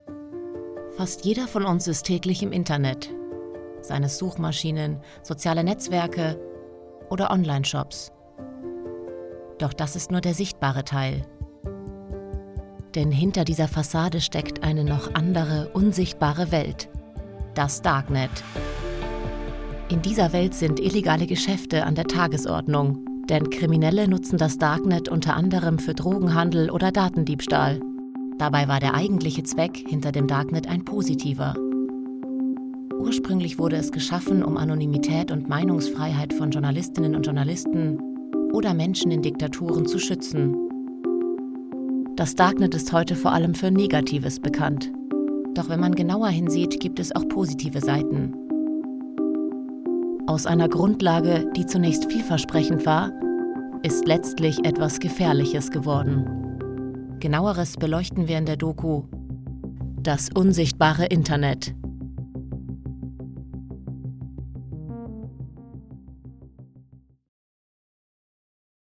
dunkel, sonor, souverän, hell, fein, zart, markant, plakativ, sehr variabel
Mittel minus (25-45)
Darknet Dokumentation Voice Over
Doku